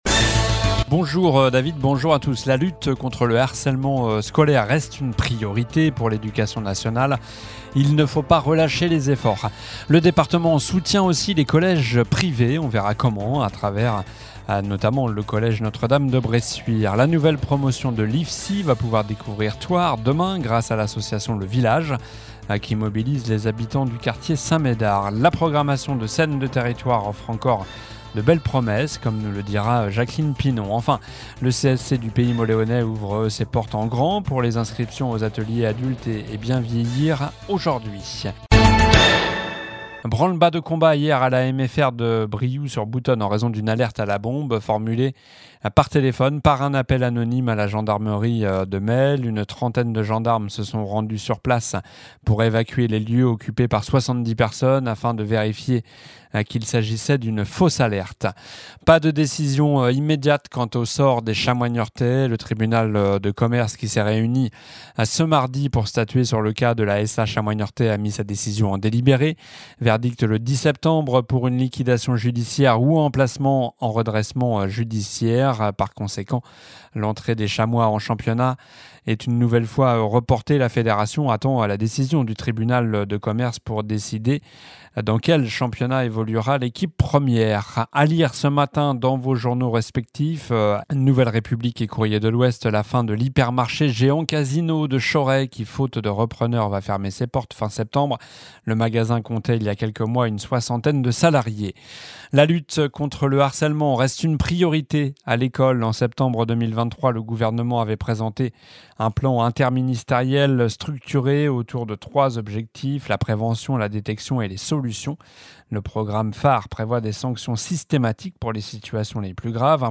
Journal du mercredi 04 septembre (midi)